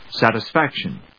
音節sat・is・fac・tion 発音記号・読み方
/s`æṭɪsfˈækʃən(米国英語)/